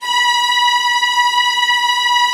VIOLINS C#-L.wav